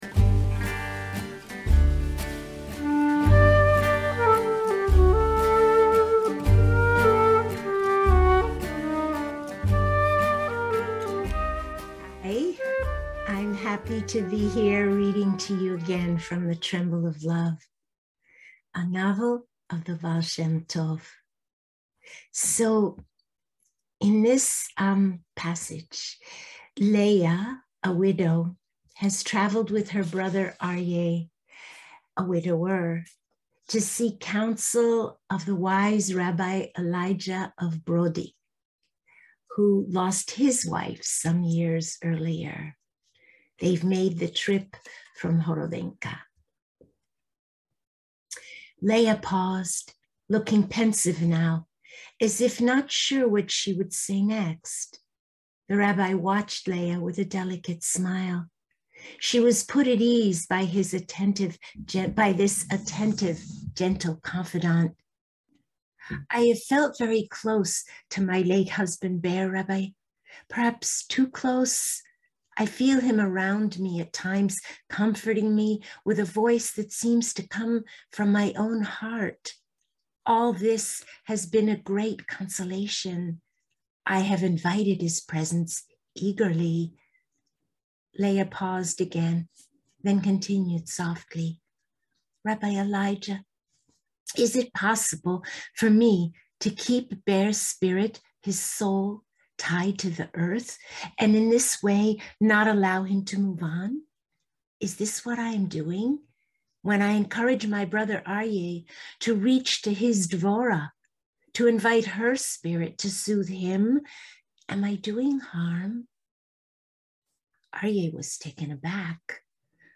I am grateful for the opportunity to read to you again and in this way to meet again at the well of Love.